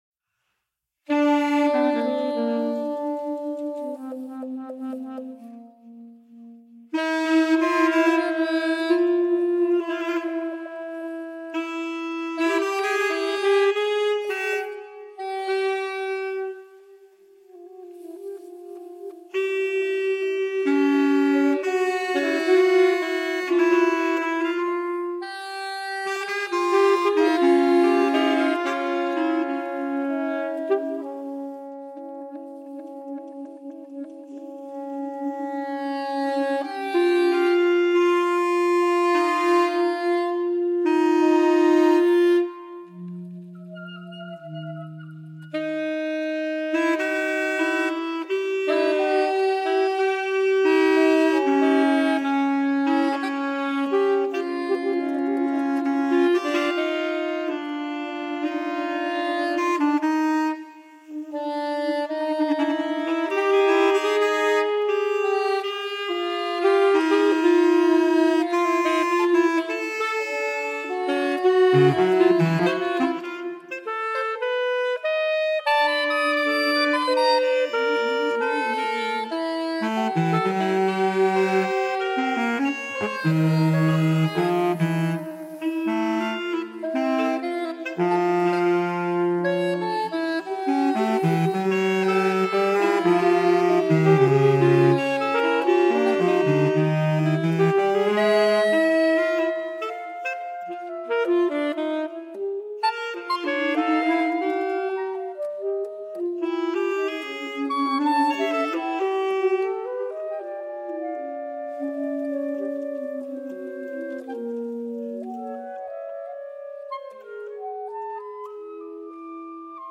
saxophone quartet